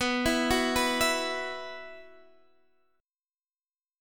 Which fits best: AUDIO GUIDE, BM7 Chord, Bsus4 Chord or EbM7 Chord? Bsus4 Chord